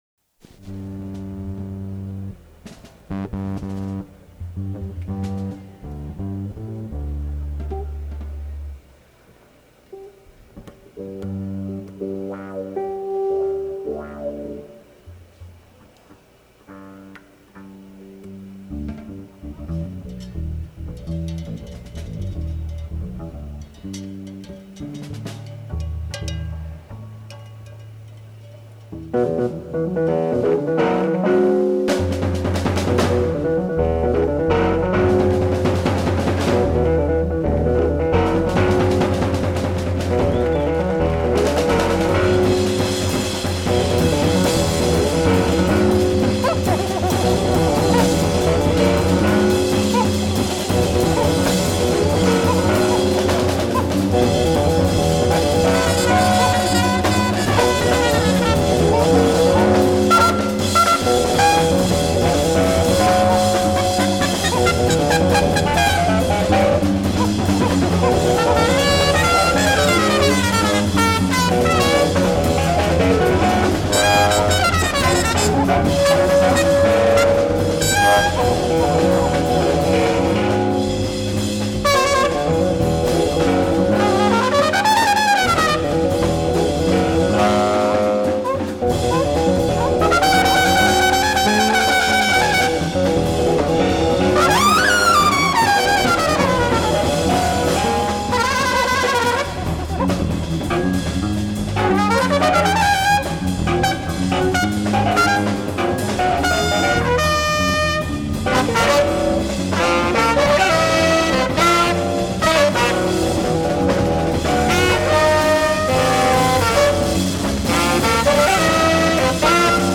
Disc Two: Second Show